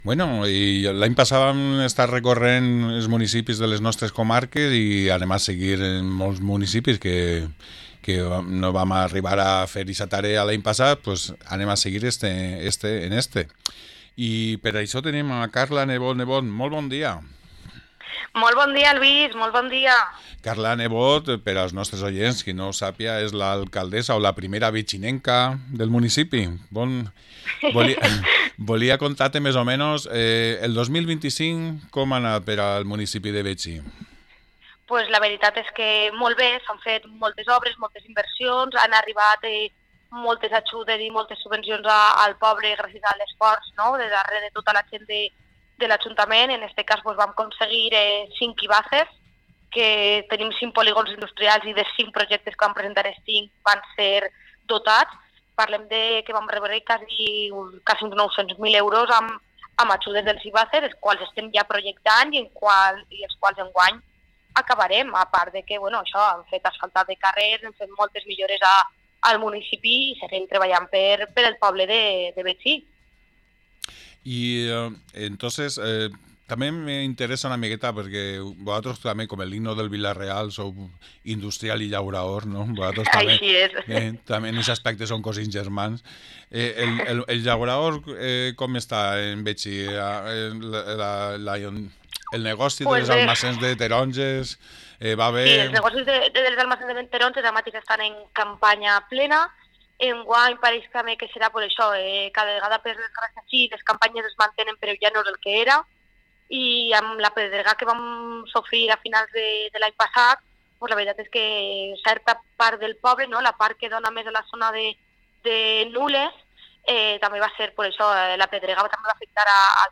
📢 Festes de Sant Antoni a Betxí, ens ho explica la seua alcaldessa Carla Nebot
08-01-26-carla-nebot-alcaldessa-betxi.mp3